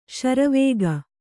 ♪ śara vēga